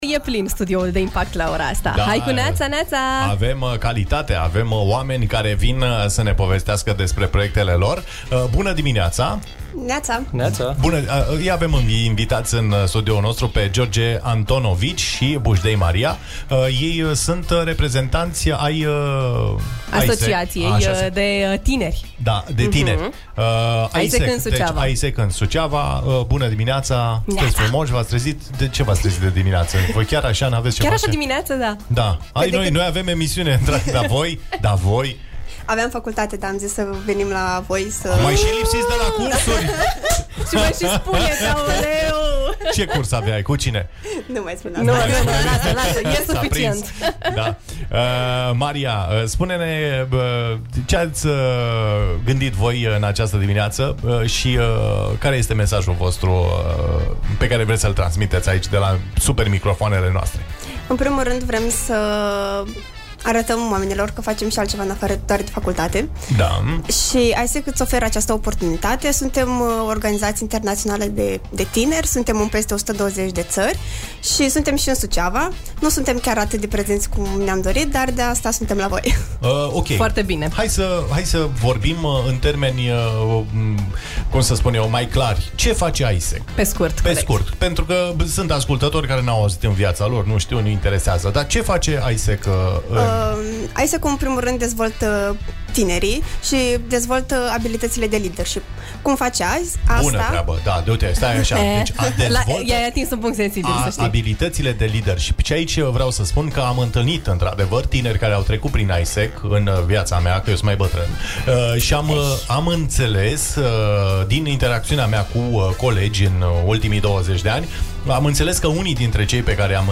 Iar dacă vreţi să aflaţi detalii suplimentare, vă invităm să ascultaţi interviul integral (şi extrem de energic) mai jos: